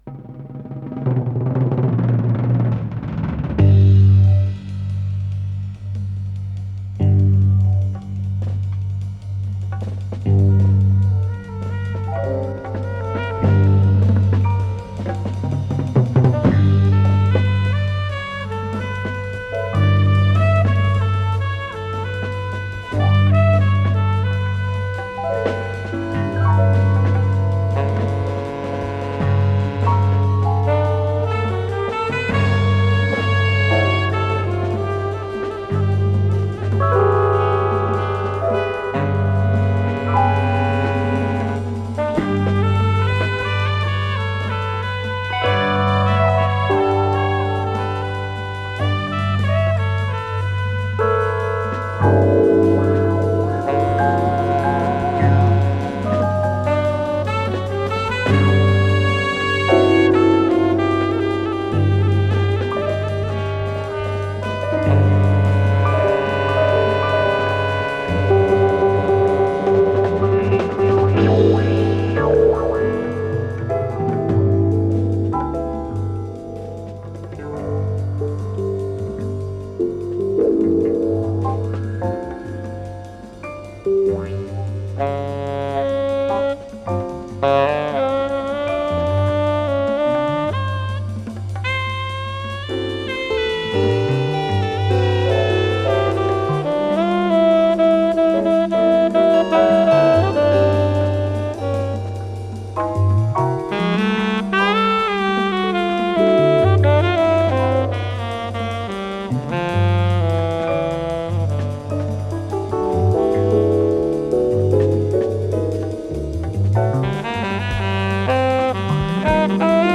Пьеса для инструментального ансамбля, соль мажор